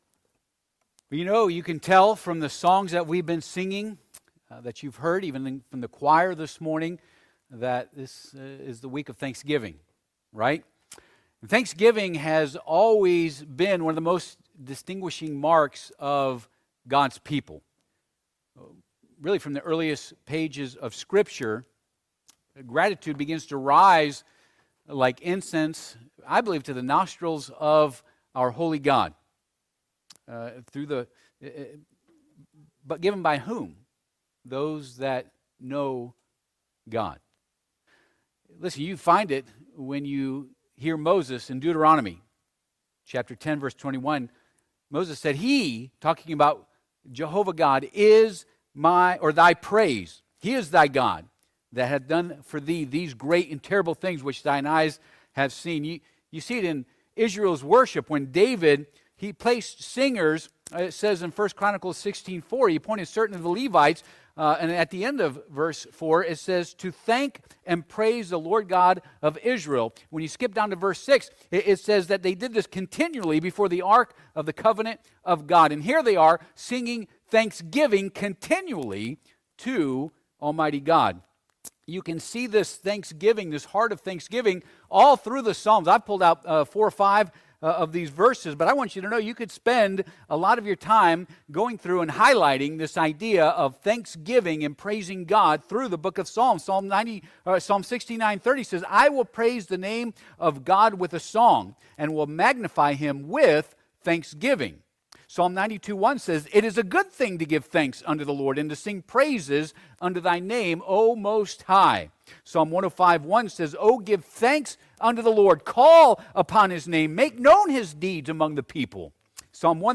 35 Service Type: Sunday AM Topics